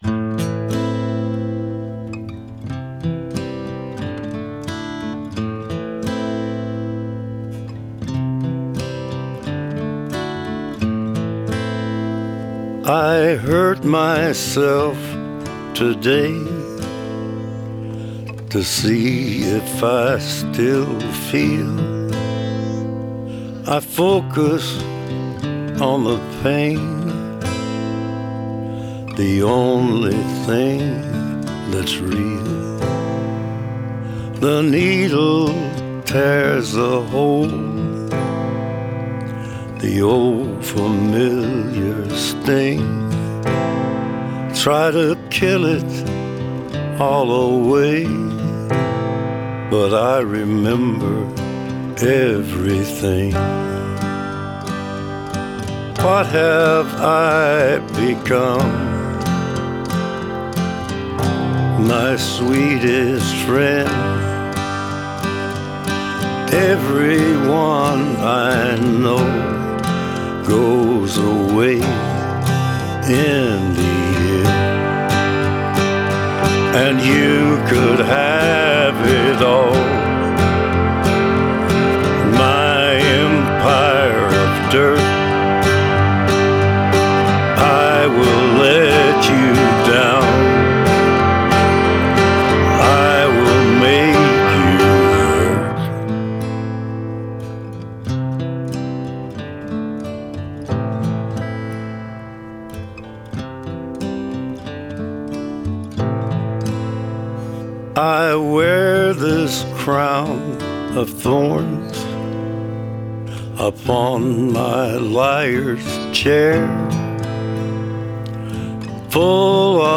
genre: folk,folk rock,acoustic rock